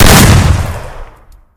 Shotgun Sound Effect Free Download
Shotgun